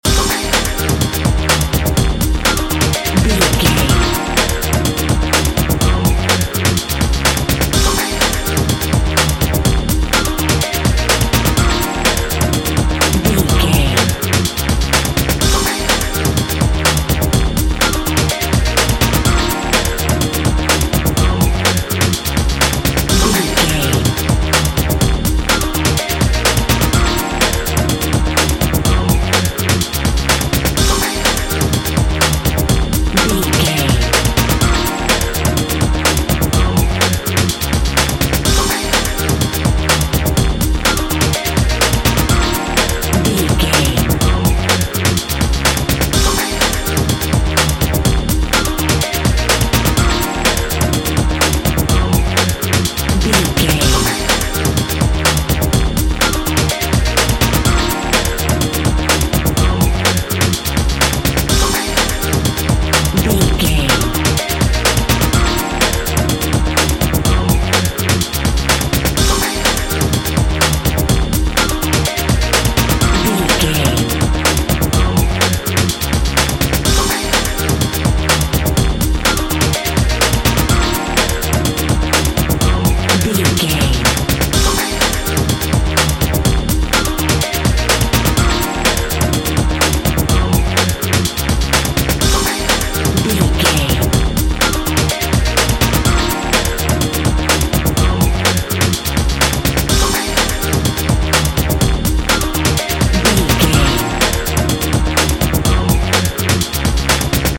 Epic / Action
Fast paced
Aeolian/Minor
E♭
energetic
driving
intense
futuristic
drum machine
synthesiser
breakbeat
power rock
synth leads
synth bass